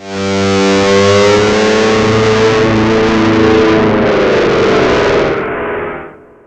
gtdTTE67010guitar-A.wav